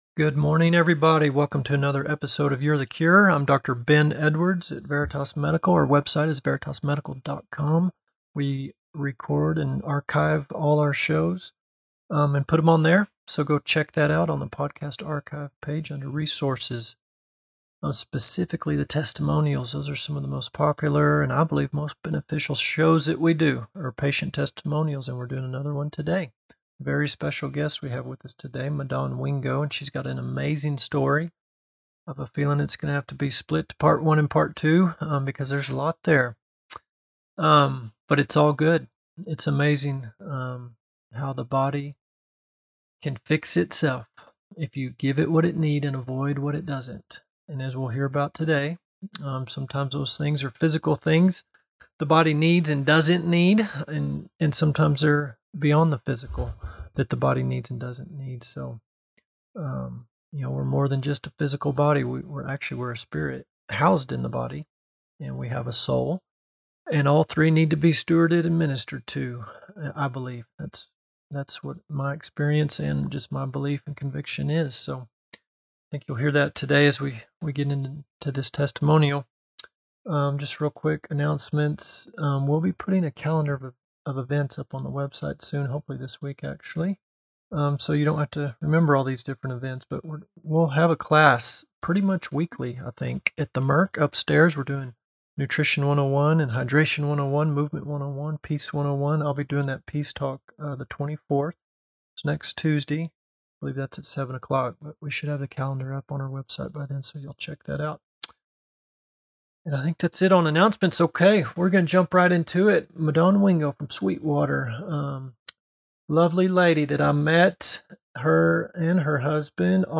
Divine Healing (testimonial)